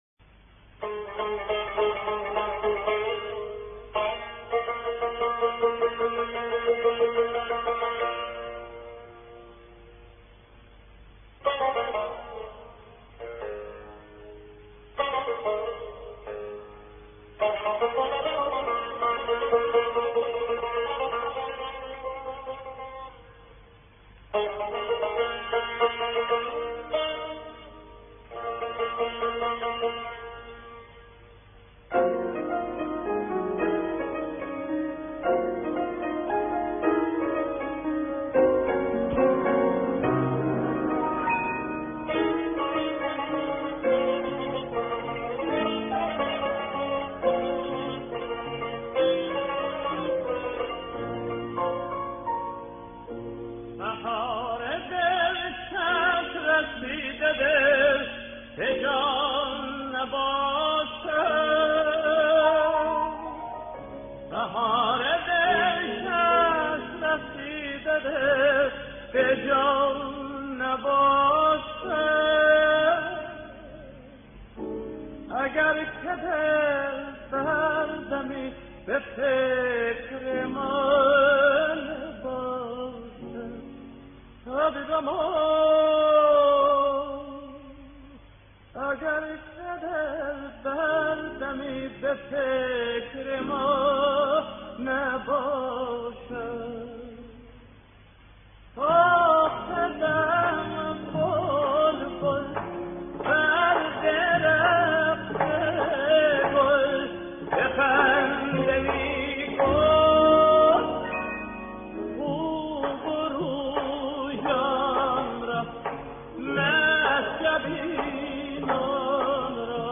از میان خوانندگان مختلفی که این تصنیف را اجرا کرده اند، یکی رشید بهبودف، خواننده شهیر کشور آذربایجان است که در سفری به تهران در سال 1342 آن را خوانده است.
پیانو
تار) او را در یک کنسرت دو هفته که دو ماه به طول انجامید همراهی می کردند.